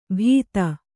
♪ bhīta